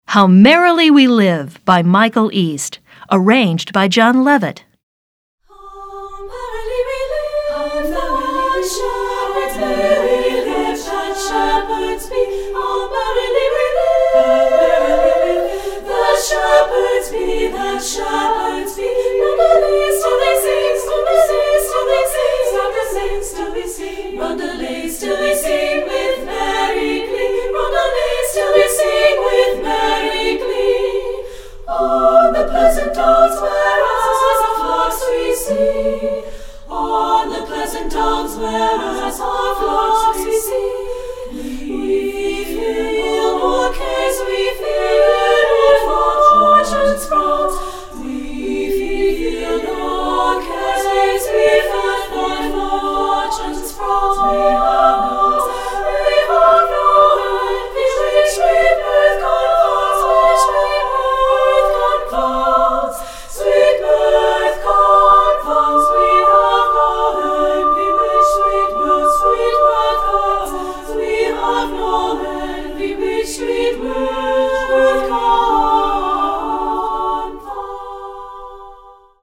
Voicing: SSA a cappella